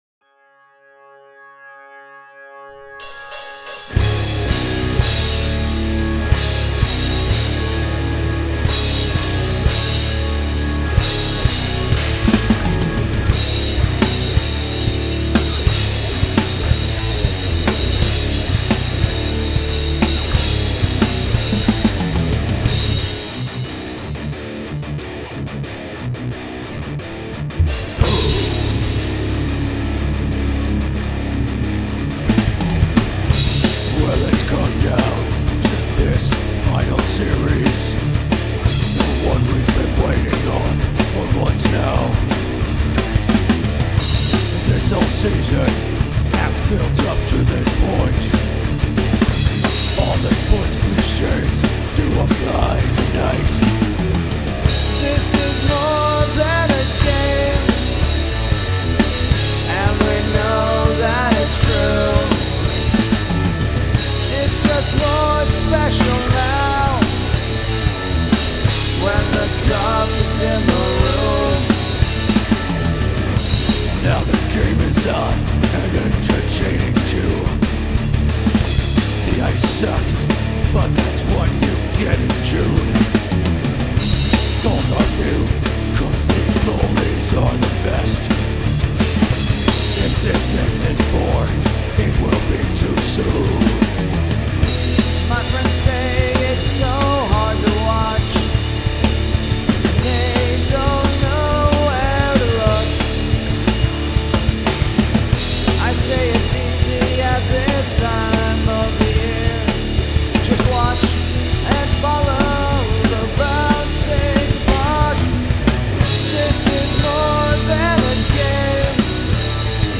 Lyrically, this one serves mostly as a wrap-up of the whole project, complete with a section of complaints and a clean section written from the perspective of that guy whose job is to watch over the Stanley Cup. Like usual, I started with the singing sections first, then went to the harsh vocals.
I really like the dynamic contrast that the middle section provides – one good way to ramp up the intensity in metal is to back off for a while.
That included a rarity for me – a keyboard solo. And to think I played it on a two-octave MIDI controller…